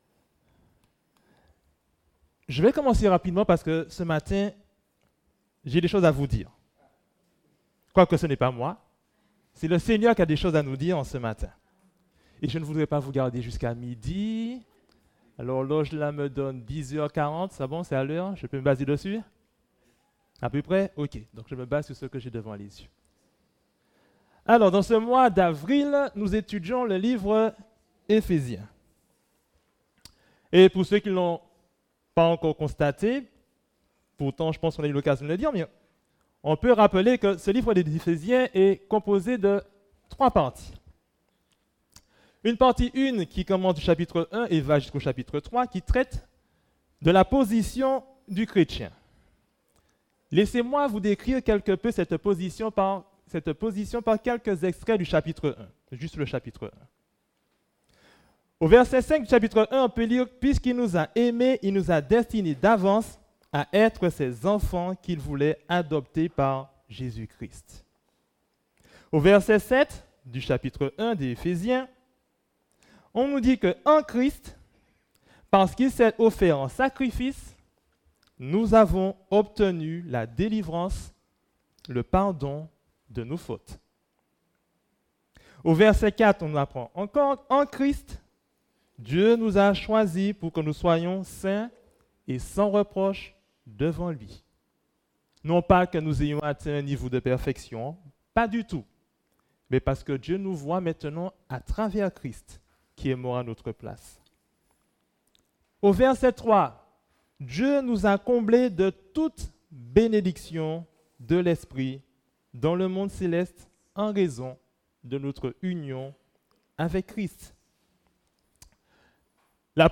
Le diable rode … soyez équipés Prédicateur